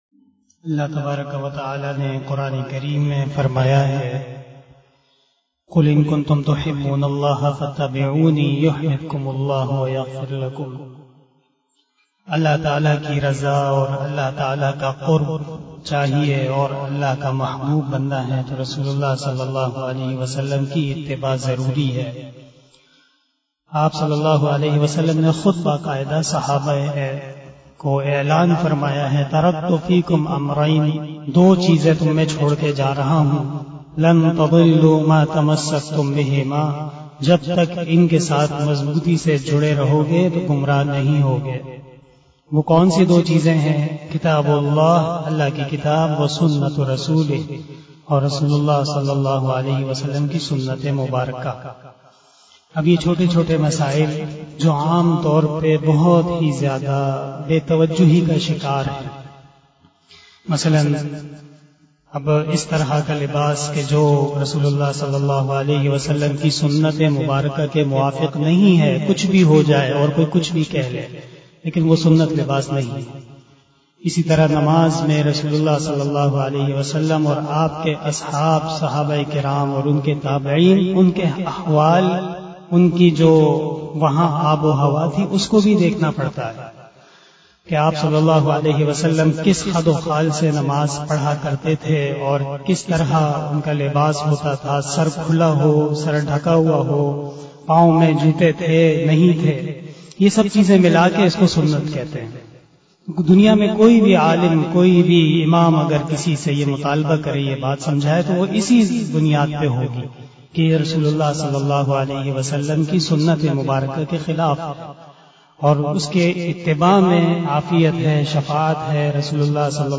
049 After Asar Namaz Bayan 30 August 2021 (21 Muharram 1443HJ) Monday